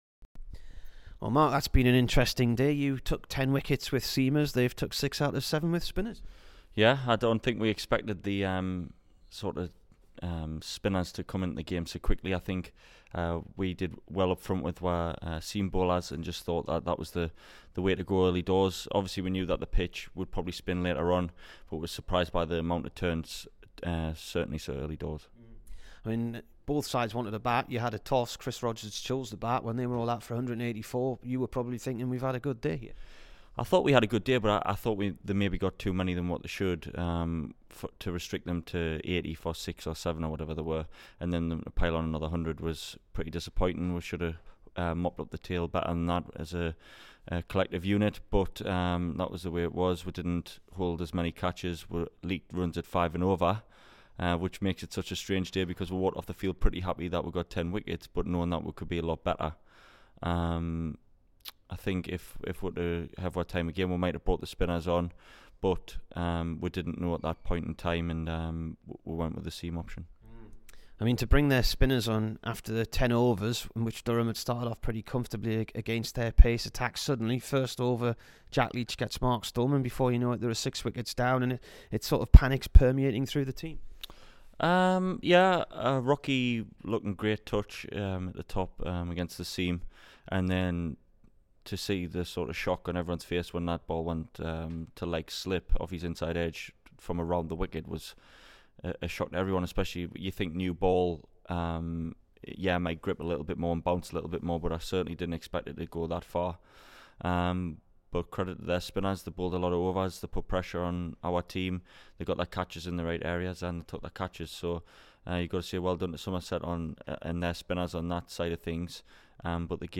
Mark Wood interview